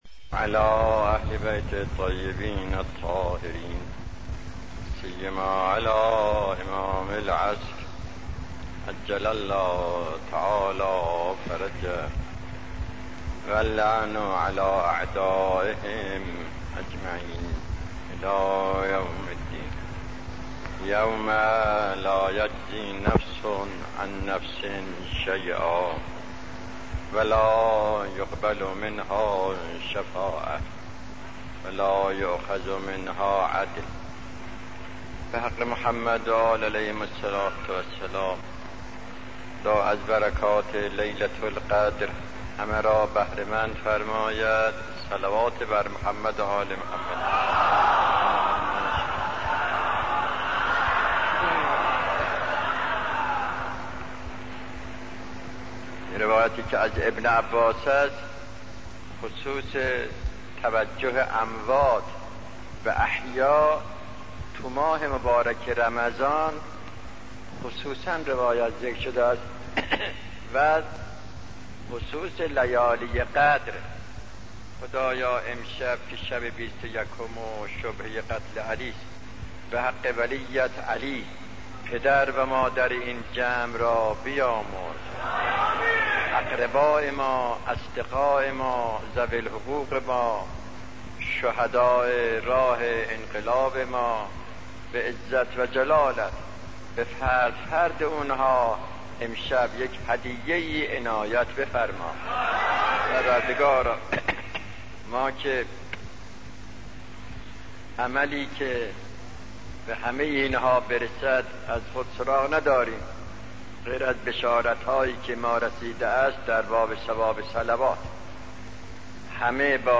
سخنرانی شهید آیت الله دستغیب، با موضوع توجه اموات به احیا، در ماه مبارک رمضان